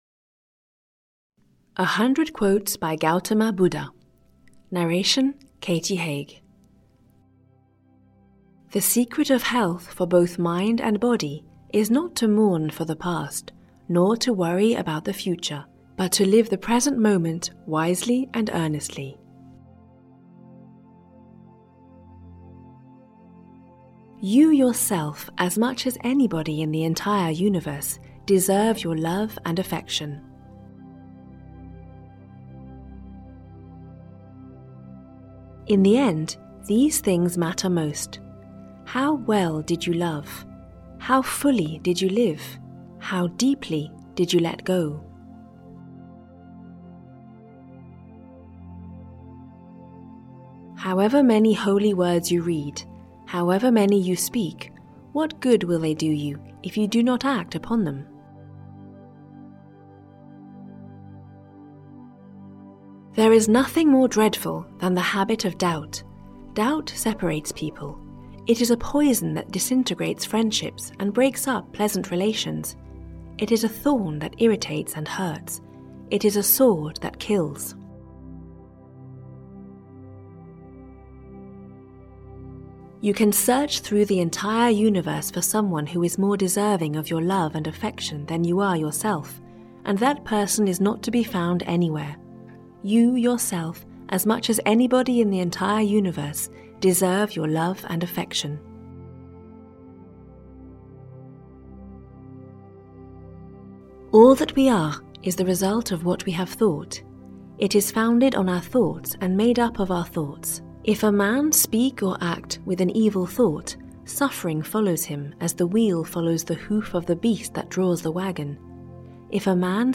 Audio kniha100 Quotes by Gautama Buddha: Great Philosophers & Their Inspiring Thoughts (EN)
Ukázka z knihy